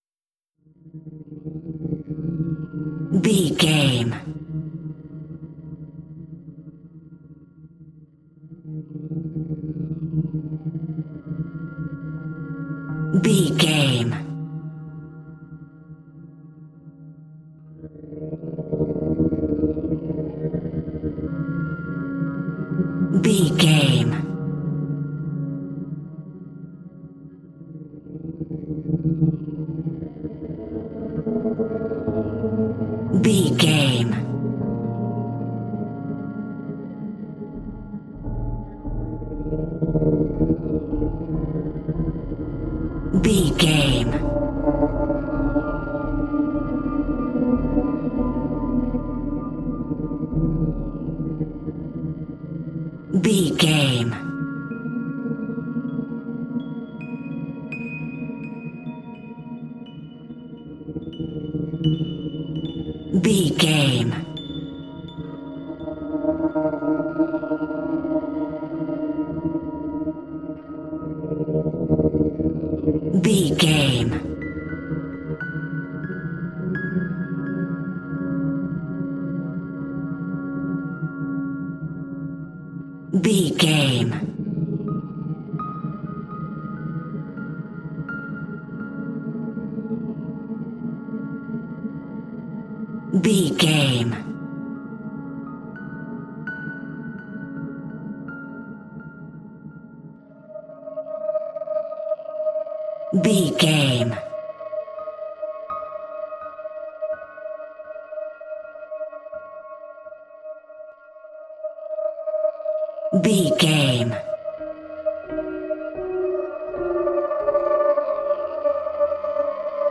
Dark Horror Film Atmos.
Aeolian/Minor
ominous
suspense
eerie
creepy
Horror Pads
horror piano
Horror Synths